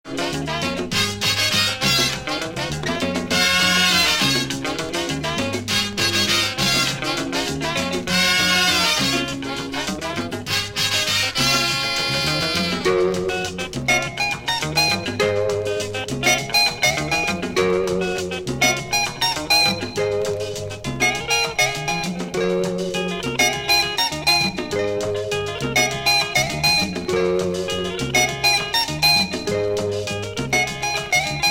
Mono Country
Género: Latin, Folk, Worlk & Country Estilo: African, Rumba